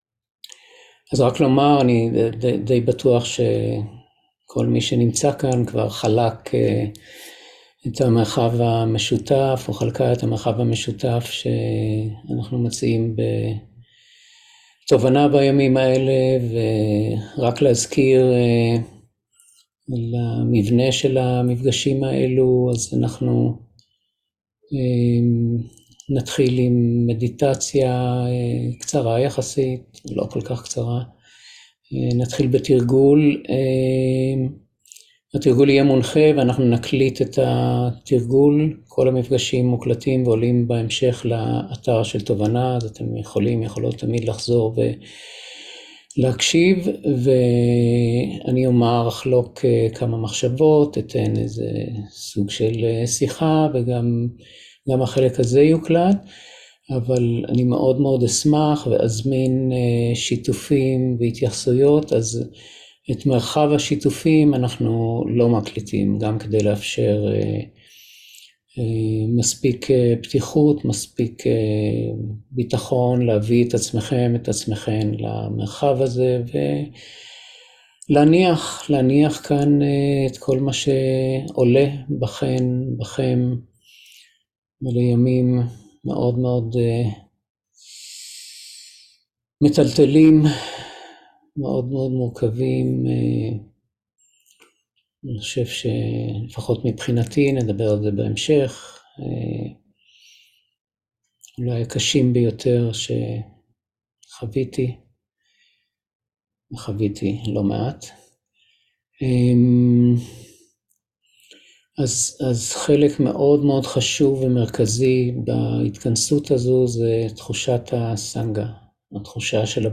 27.10.2023 - מרחב בטוח - תרגול מדיטציה